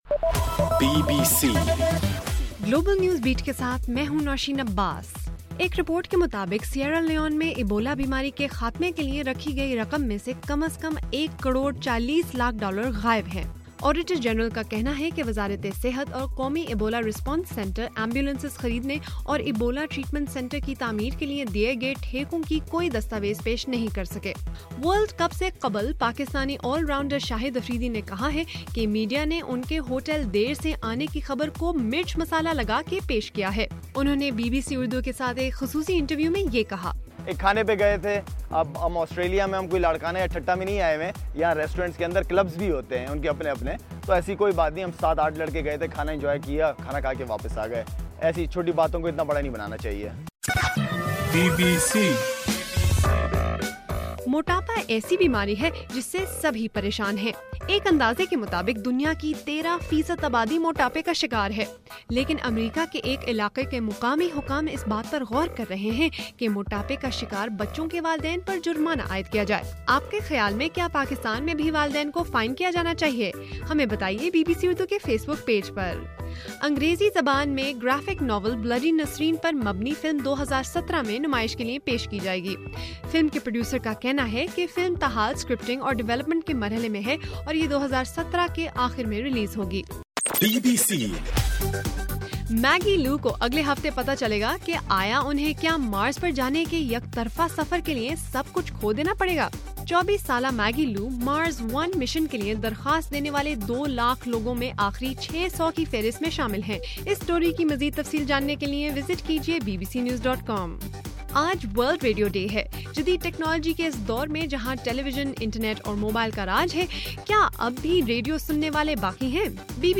فروری 13: رات 11 بجے کا گلوبل نیوز بیٹ بُلیٹن